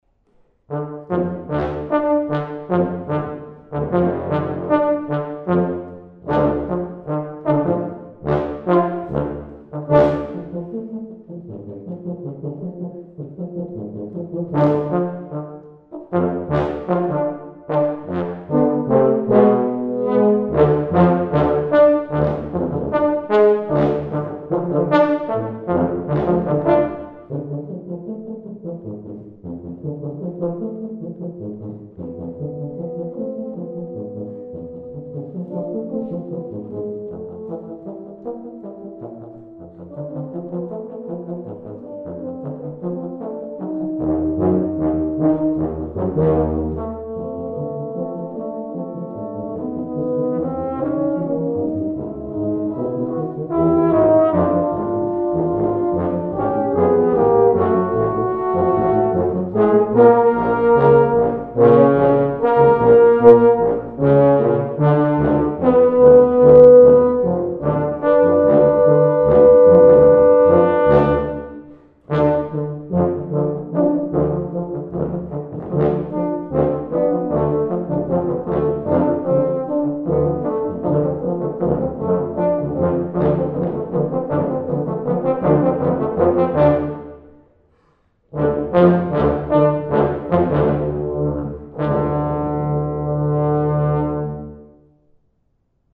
For Tuba Quartet (EETT)